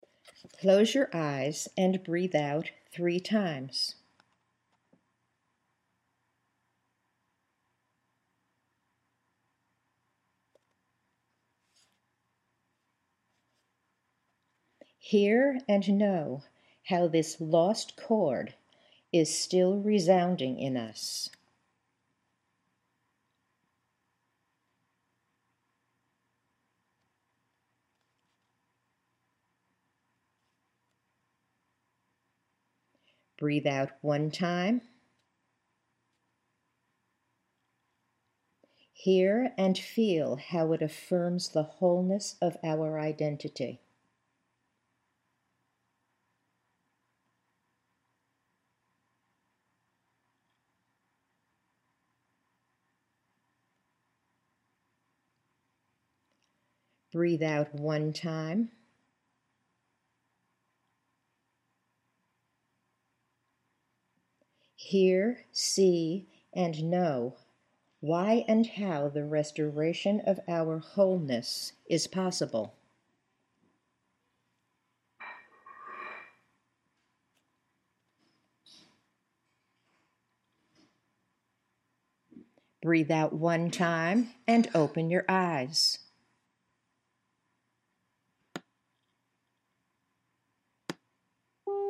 There are ten seconds of silence on the tape for your breathing, followed by the Imagery exercises.
You’ll notice silences on the audio after the breathing instructions, typically ten seconds for breathe out three times and fewer seconds for fewer breaths.